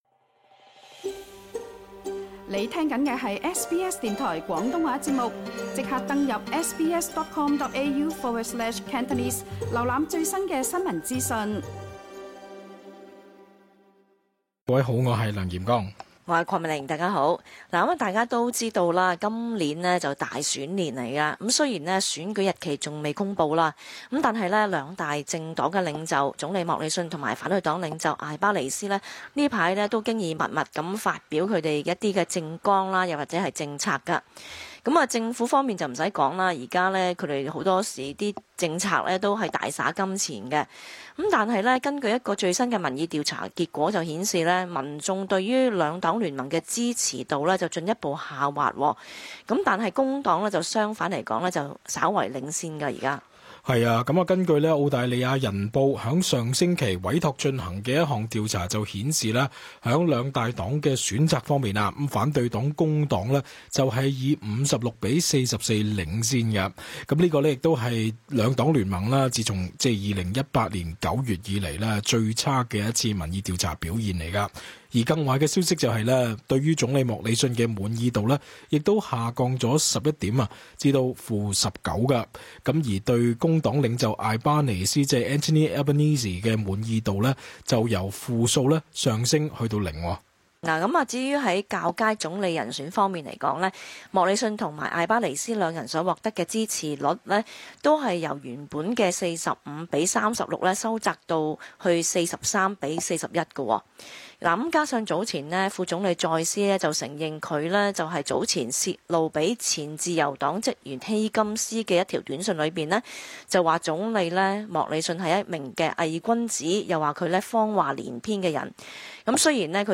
cantonese_talkback_-_upload_-_feb_10-fianl.mp3